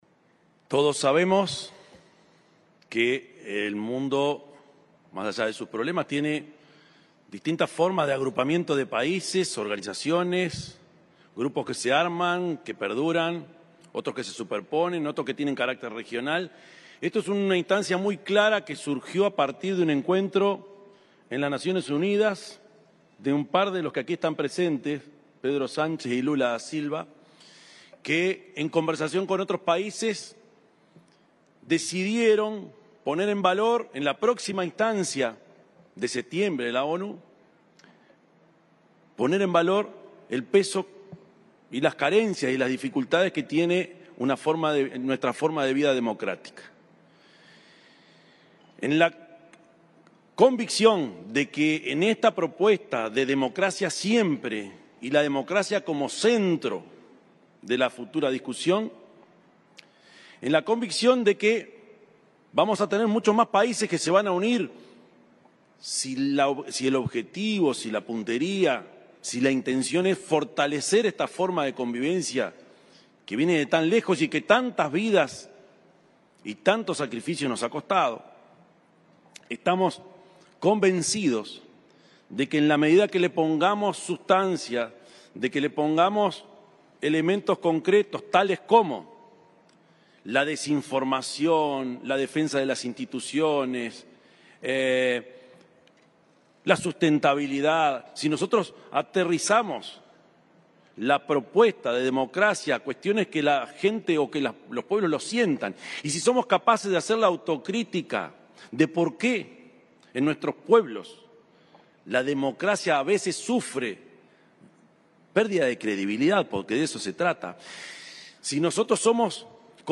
Palabras del presidente de la República, Yamandú Orsi
Durante el encuentro Democracia Siempre, que se desarrolla en Chile, el presidente de la República, Yamandú Orsi, participó en una declaración